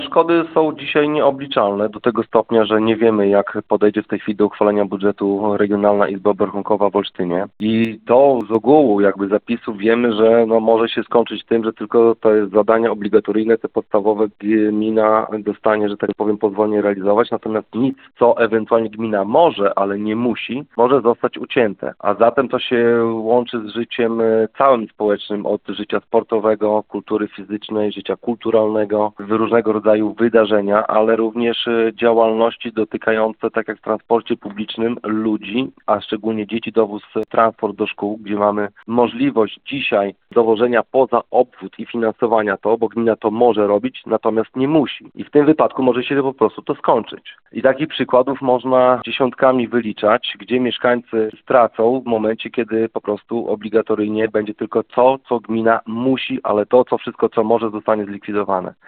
Mówi Karol Sobczak, burmistrz miasta.